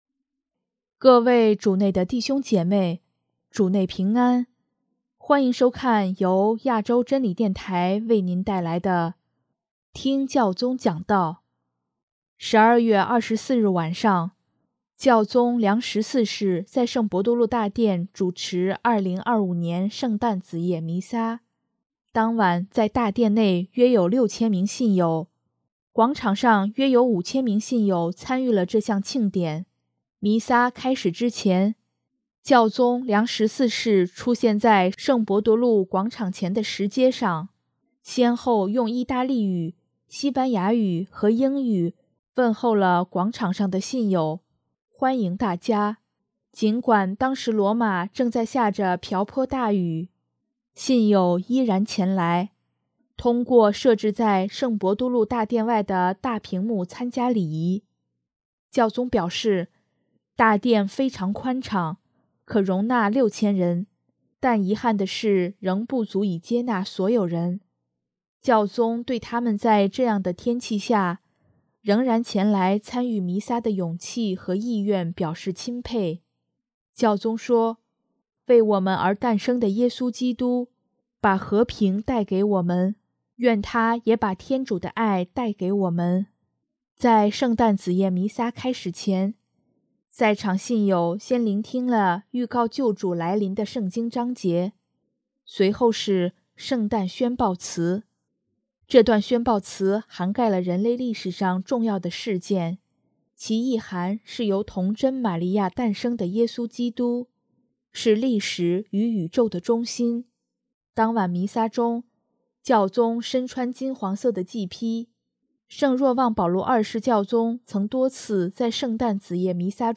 12月24日晚上，教宗良十四世在圣伯多大殿主持2025年圣诞子夜弥撒，当晚在大殿内约有6000名信友，广场上约有5000名信友参与了这项庆典。